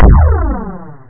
1 channel
poink.mp3